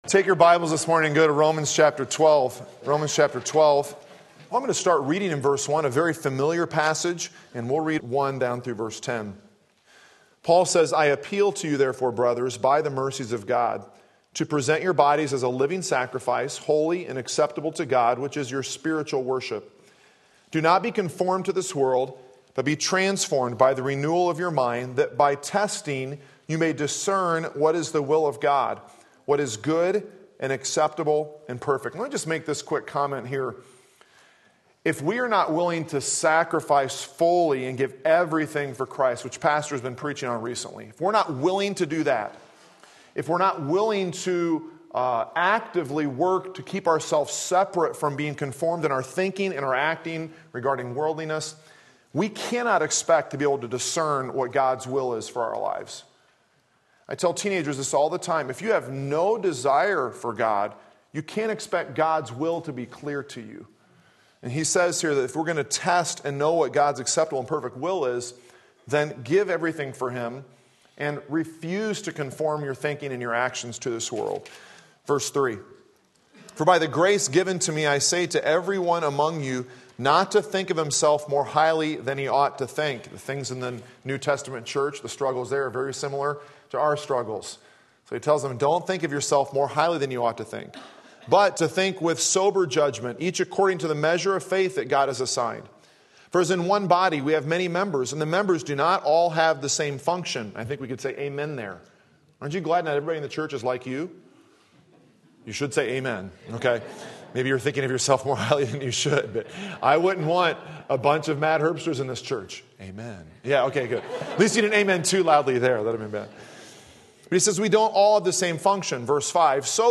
Sermon Link
Love the Brethren Romans 12:9-11 Sunday Morning Service